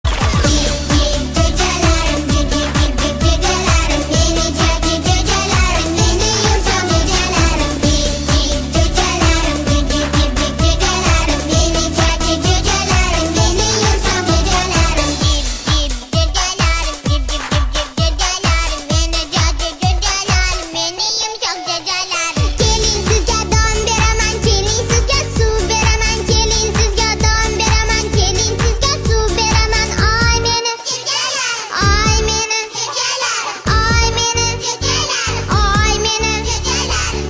• Качество: 128, Stereo
в современной обработке детскими голосами